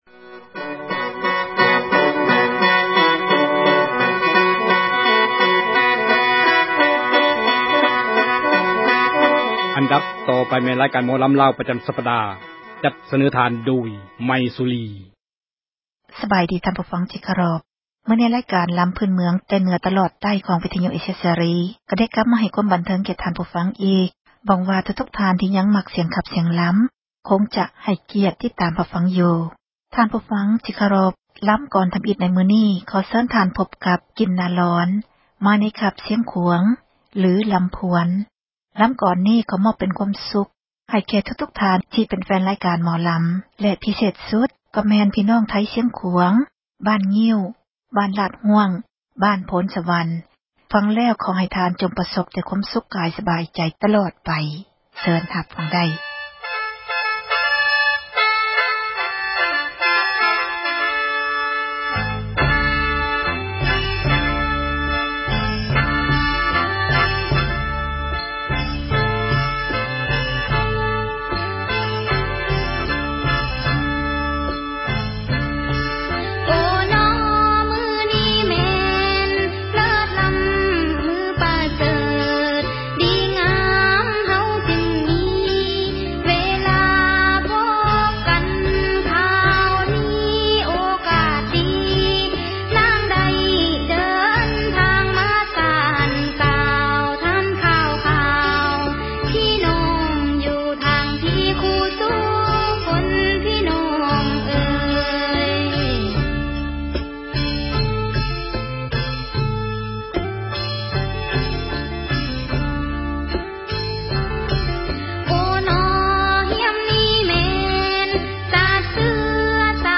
ຣາຍການໝໍລຳລາວ ປະຈຳສັປດາ.